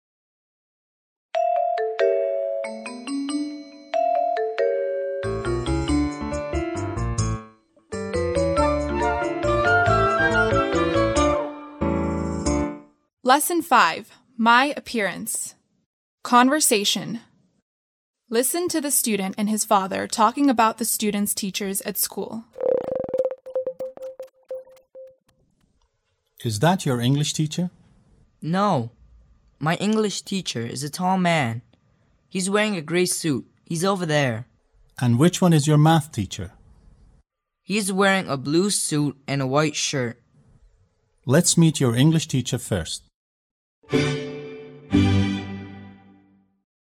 به صحبت دانش آموز و پدرش درباره معلمان دانش آموز در مدرسه گوش دهید.
7-29-Lesson5-1-Conversation.mp3